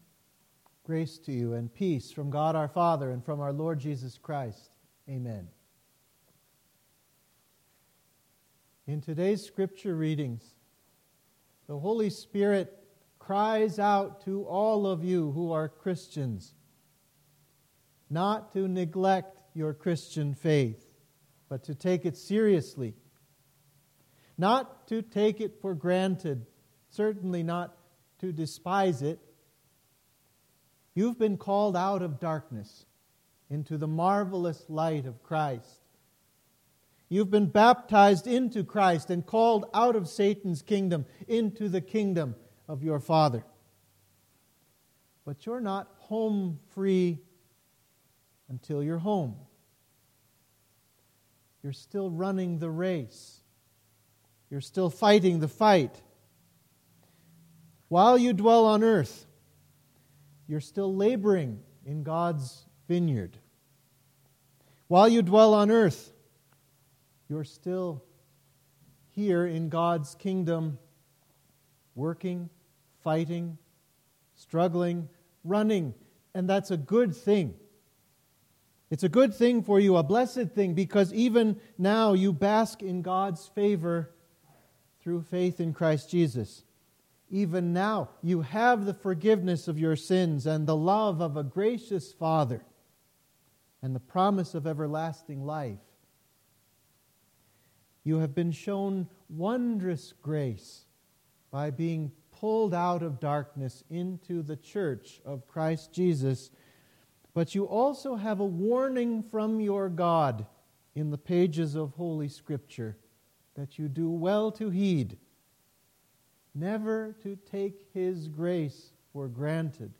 Sermon for Septuagesima